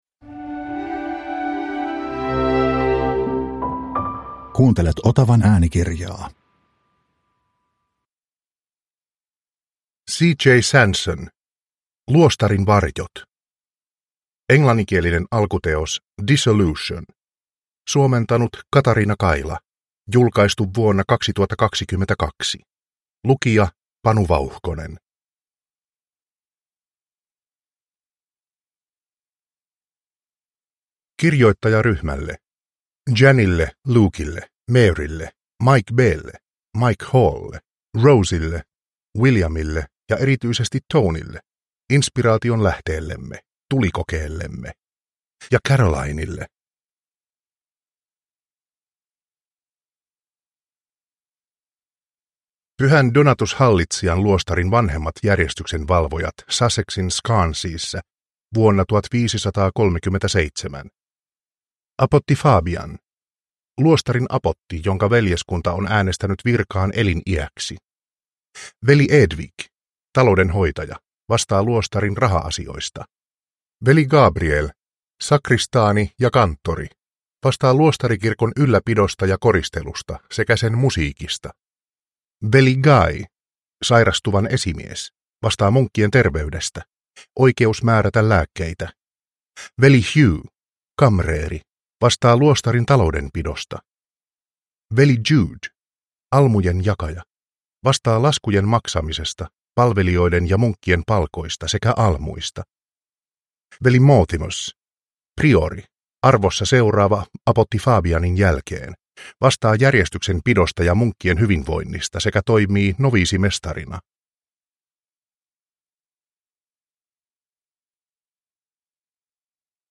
Luostarin varjot – Ljudbok – Laddas ner